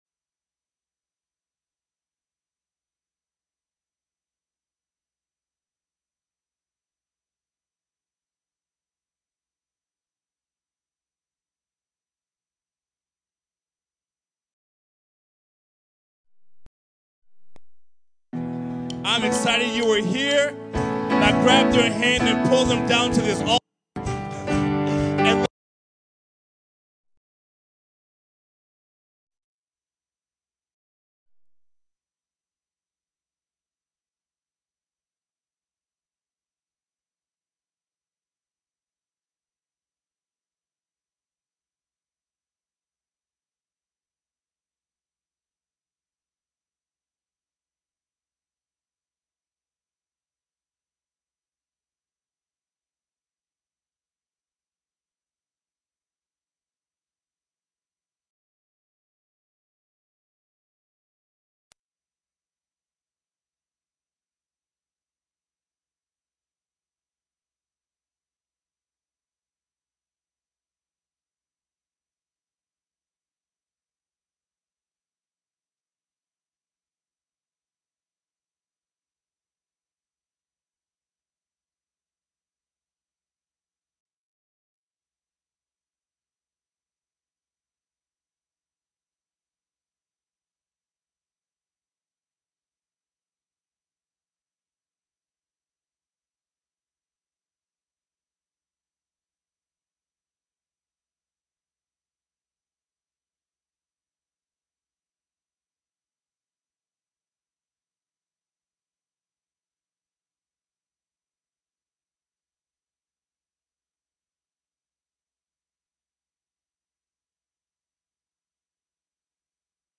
SEVEN KEY'S TO LIVING IN THE PROMISE 1ST SERVICE